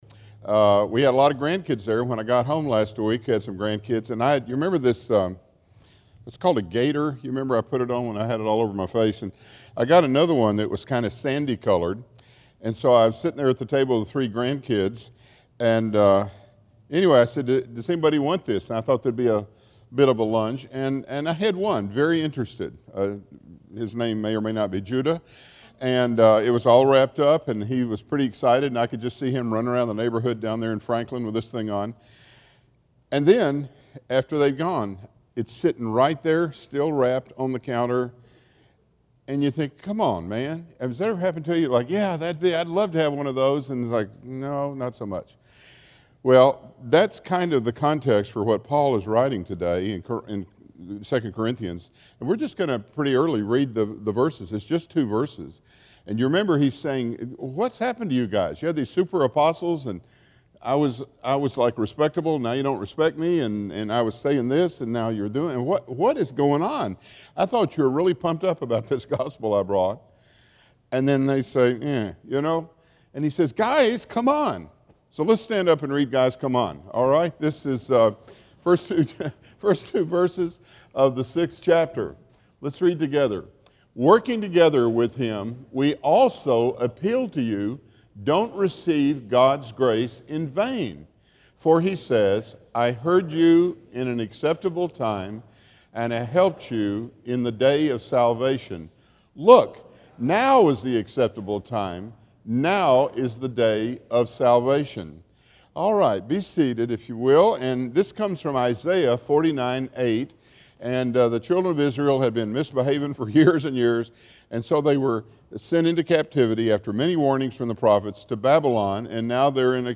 Testing Sermon